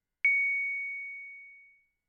Roland Juno 6 Sine pluck " Roland Juno 6 Sine pluck C6 ( Sine pluck85127)
标签： CSharp6 MIDI音符-85 罗兰朱诺-6 合成器 单票据 多重采样
声道立体声